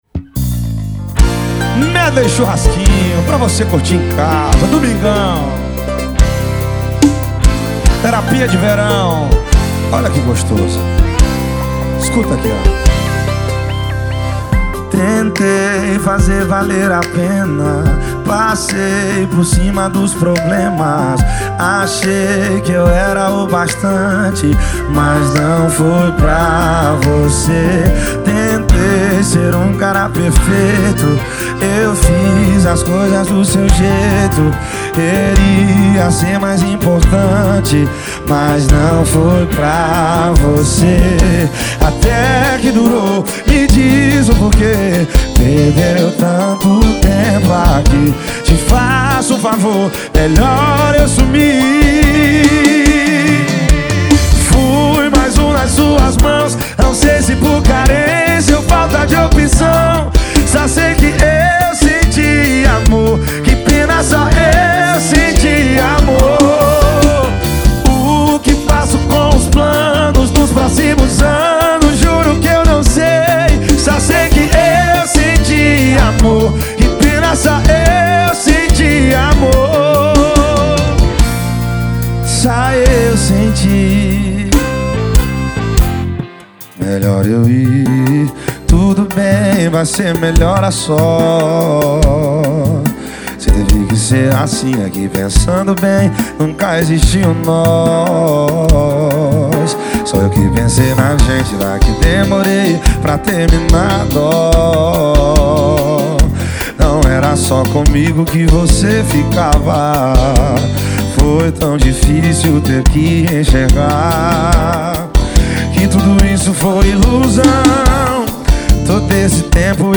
2024-02-14 18:39:47 Gênero: Forró Views